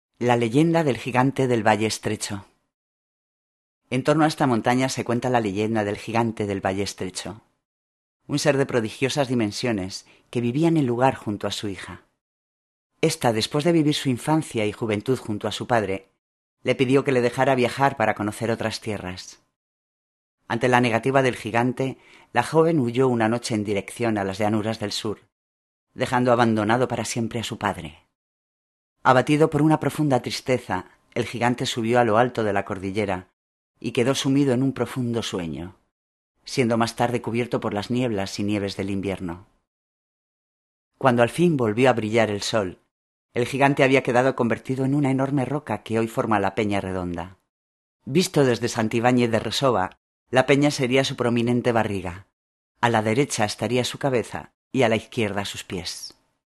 Locucion: